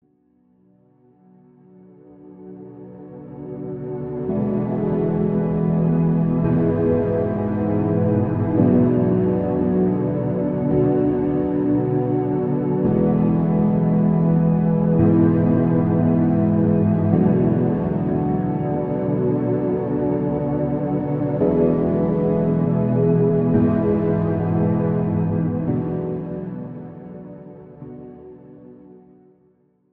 This is an instrumental backing track cover.
Key – F# / Gb
Without Backing Vocals
No Fade